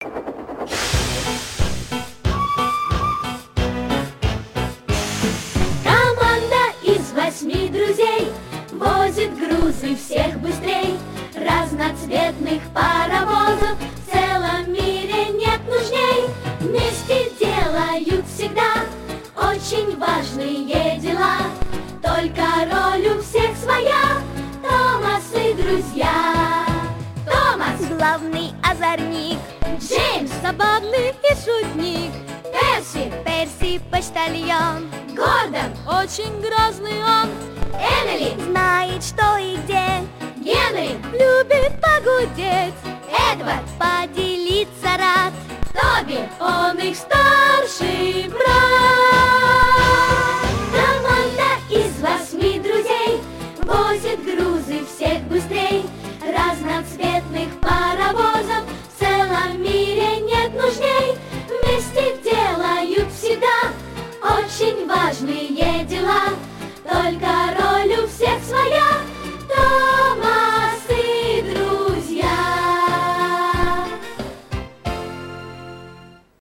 • Качество: Хорошее
• Категория: Детские песни
🎶 Детские песни / Песни из мультфильмов